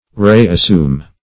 re-assume - definition of re-assume - synonyms, pronunciation, spelling from Free Dictionary
(r[=e]`[a^]s*s[=u]m")